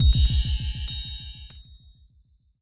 Pickup Gem Last Sound Effect
pickup-gem-last-1.mp3